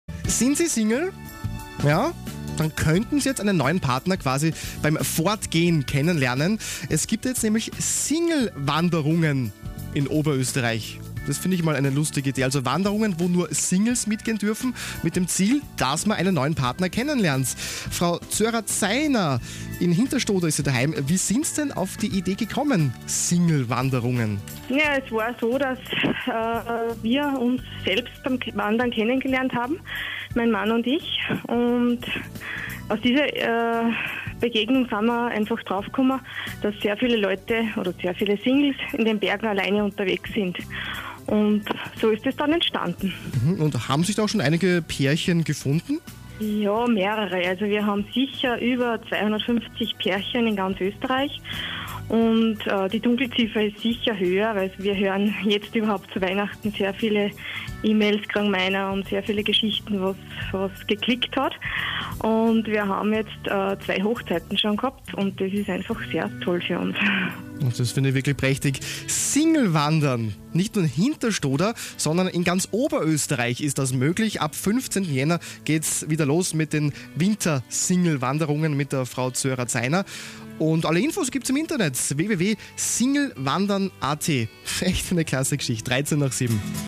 Dezember 2011 Radio Arabella     Interview von Radio Arabella über SingleWandern